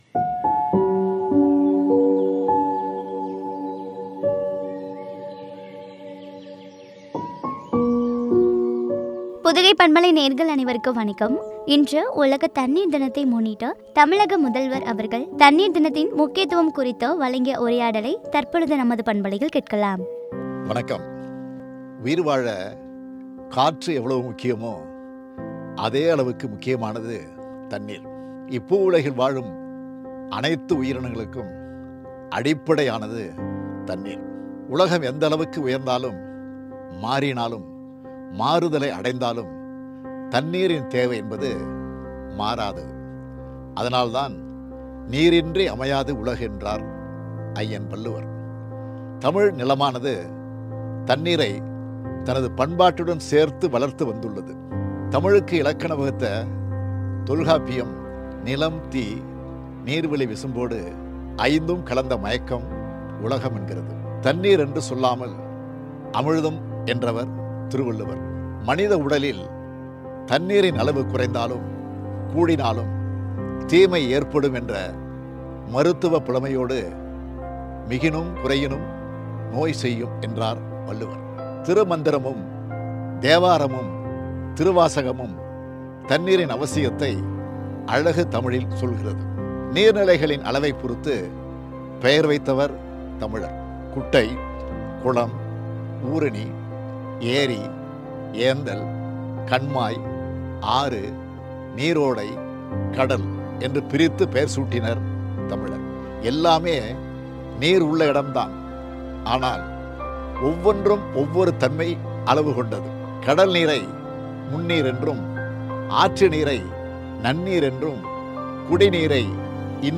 பாதுகாப்பும் பற்றிய உரையாடல்.